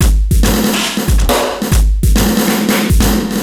E Kit 38.wav